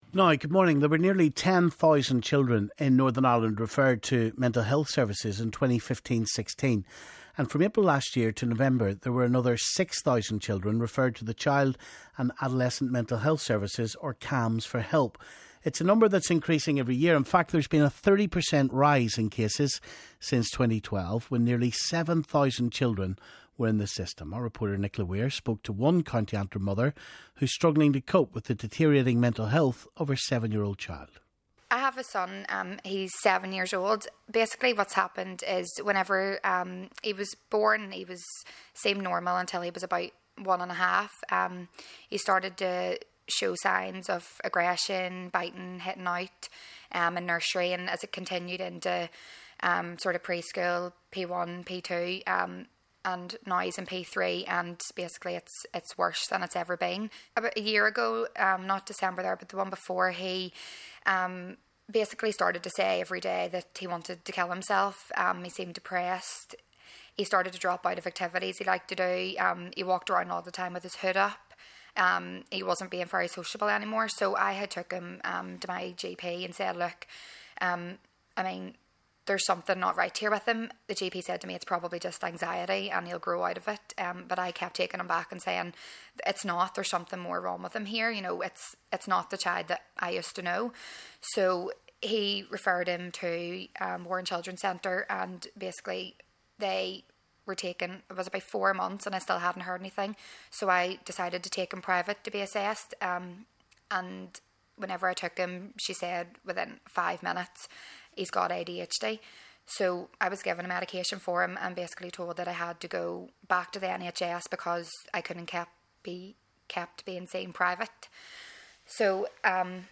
Mental health services for children in NI - one mum tells of her fears for her child